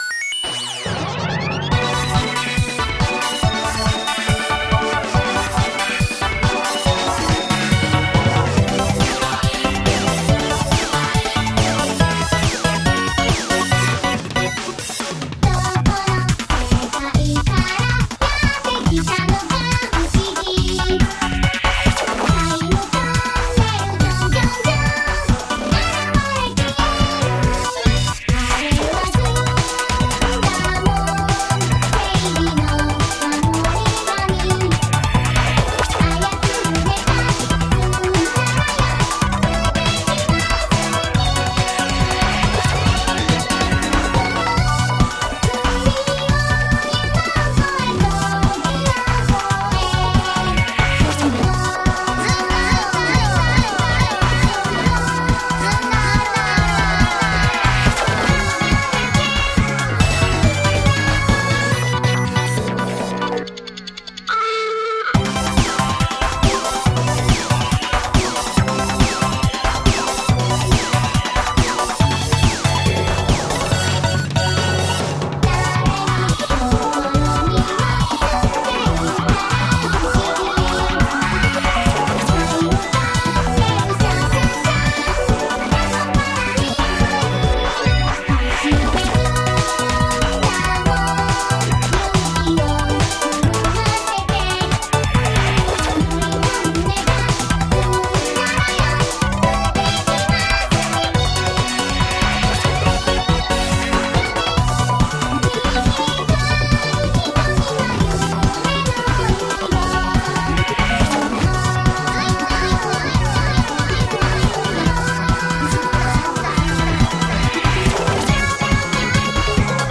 VOICEVOX
YMCK Magical 8bit Plug 2